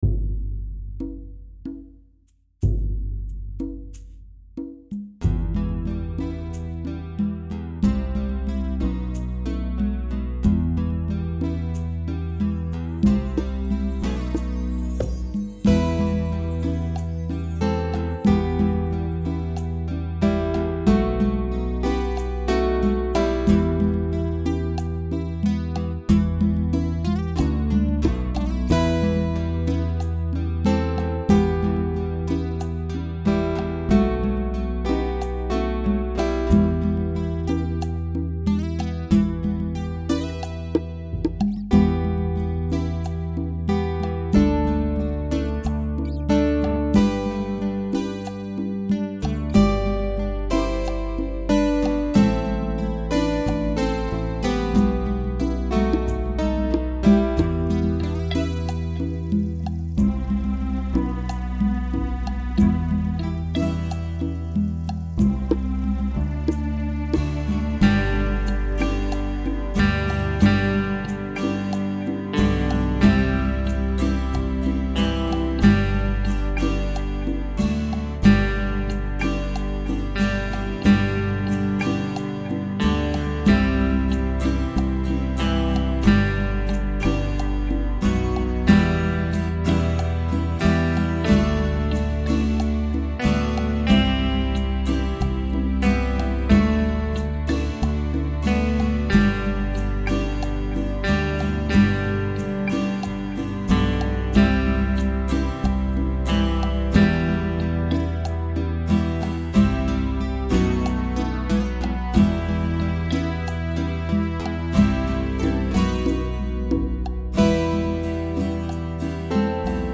Although clearly a Western piece, the music invokes a mysterious eastern feeling, and brings to mind the image of Persian kings on a long journey.